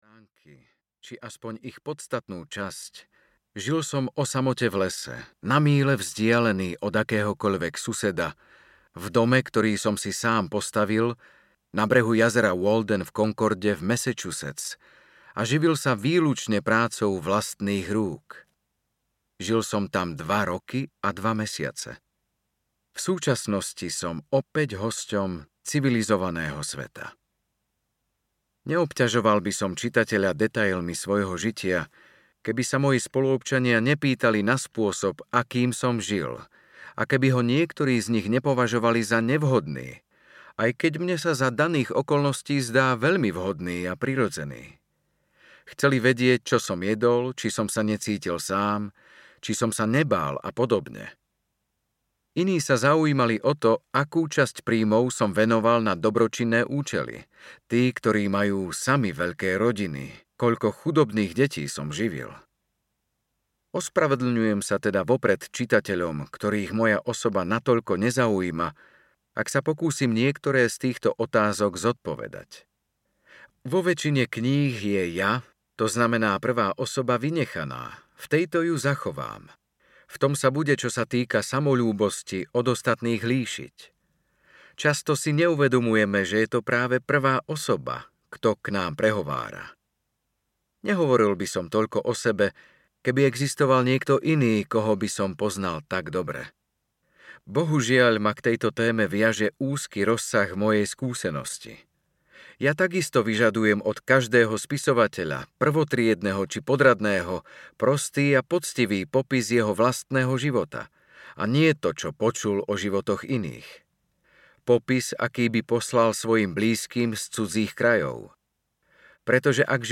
Walden alebo Život v lese audiokniha
Ukázka z knihy